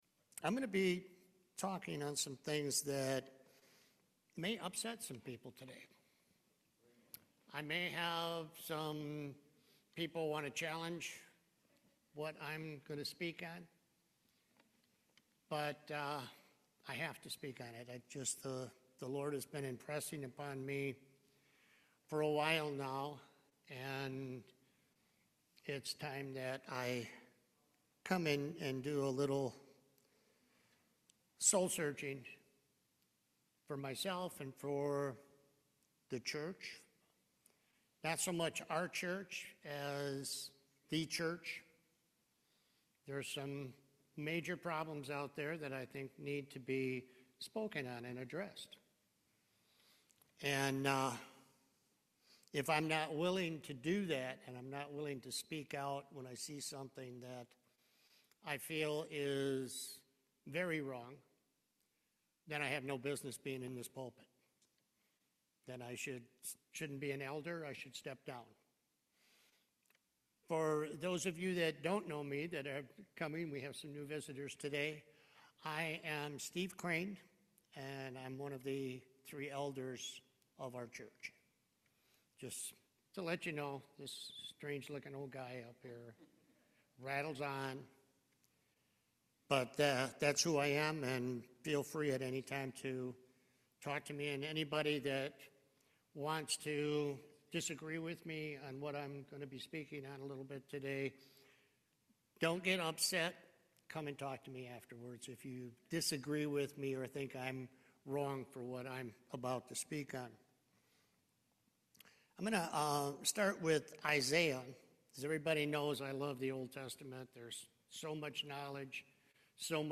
Proverbs 28:13 Service Type: Main Service Building a relationship with the Lord is critical.